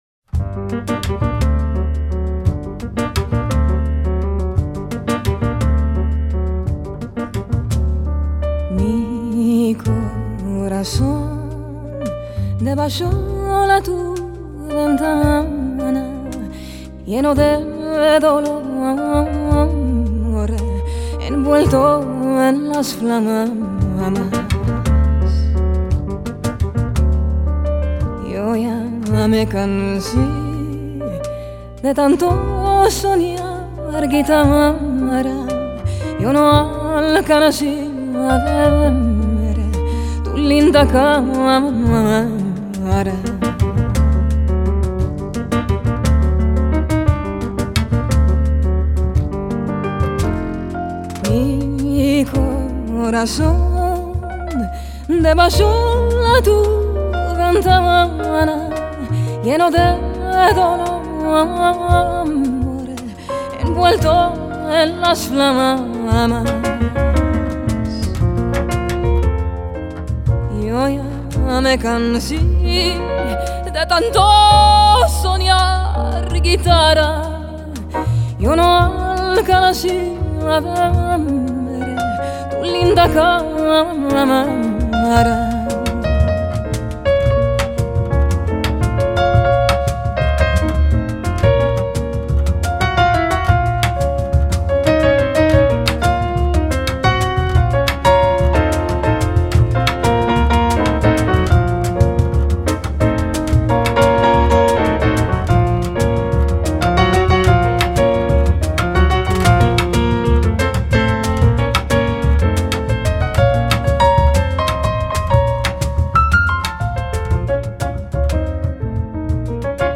Genre: Ladino